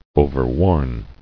[o·ver·worn]